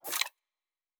pgs/Assets/Audio/Sci-Fi Sounds/Weapons/Weapon 11 Foley 2 (Rocket Launcher).wav
Weapon 11 Foley 2 (Rocket Launcher).wav